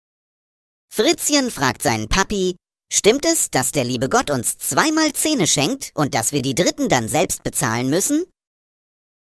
Vorgetragen von unseren attraktiven SchauspielerInnen.